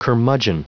Prononciation du mot curmudgeon en anglais (fichier audio)
Prononciation du mot : curmudgeon